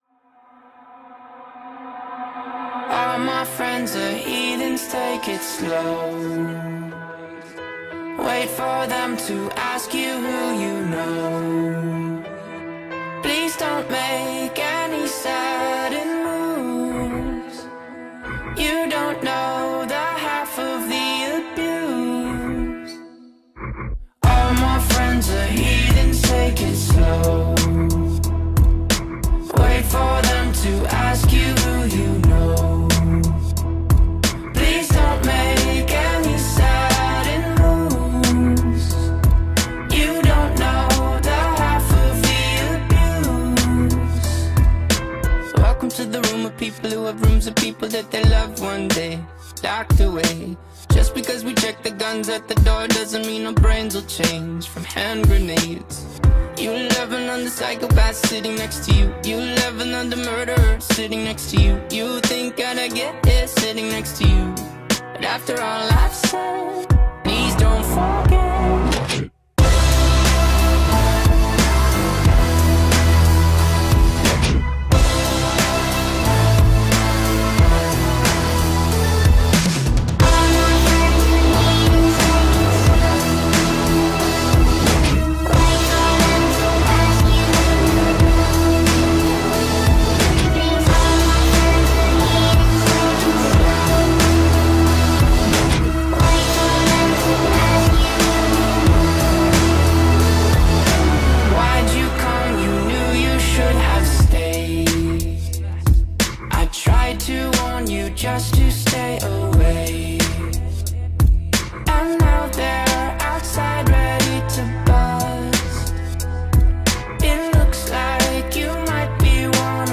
BPM90-92
MP3 QualityMusic Cut